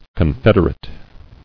[con·fed·er·ate]
Con*fed"er*a*tive (? ∨ ?), a. Of or pertaining to a confederation.